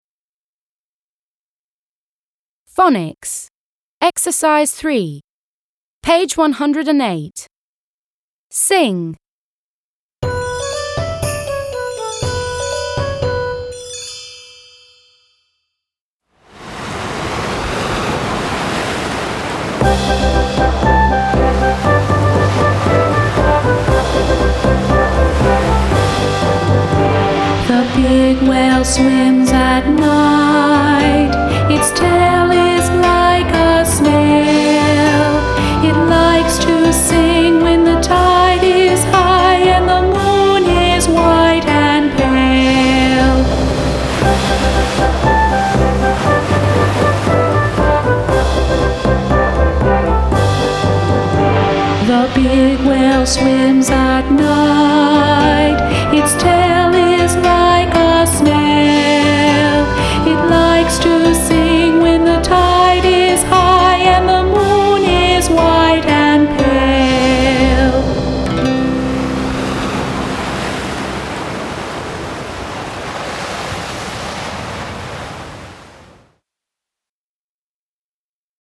3. Sing. — Спой.